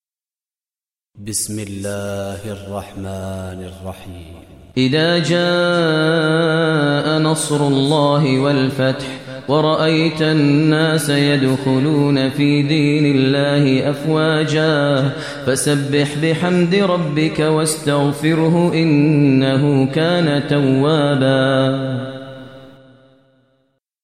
Surah Nasr Recitation by Maher Mueaqly
Surah Nasr, listen online mp3 tilawat / recitation in Arabicic recited Imam e Kaaba Sheikh Maher al Mueaqly.